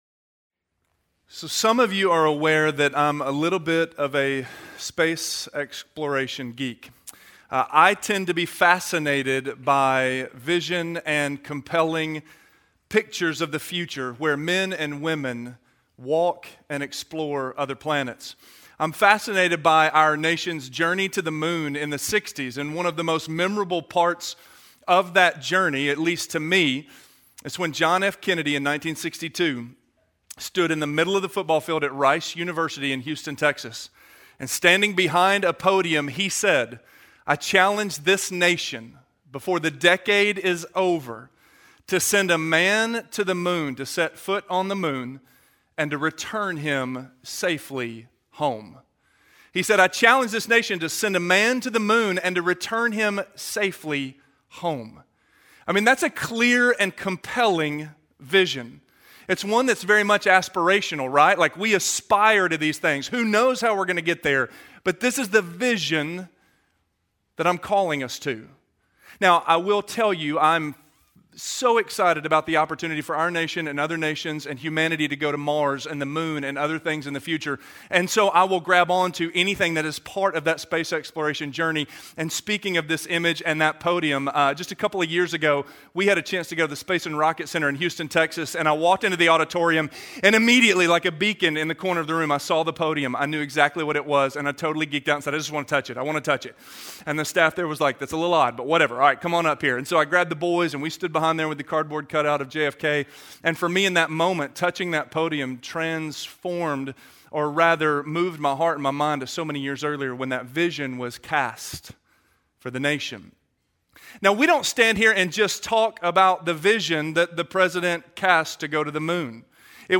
The Church Sent - Sermon - Avenue South